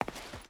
Stone Walk 3.ogg